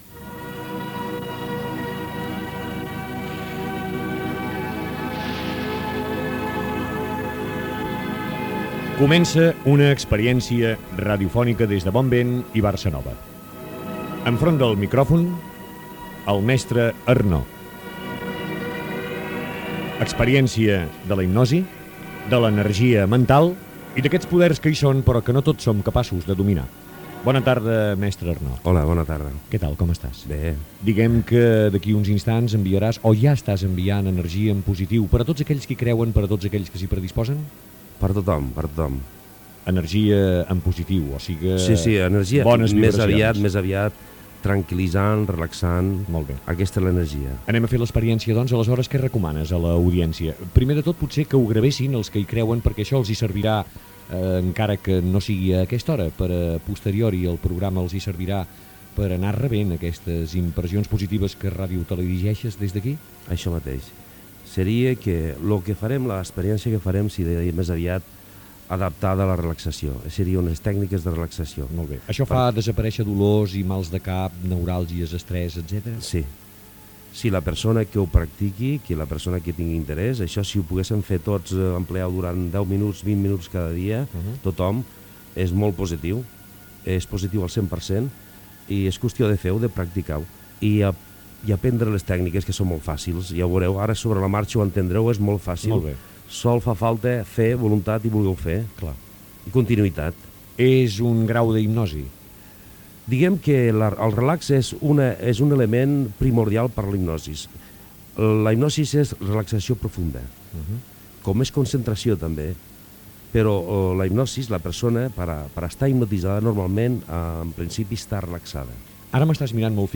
Entrevista
Durant l'espai s'adreça a l'audiència amb la voluntat de relaxar-la.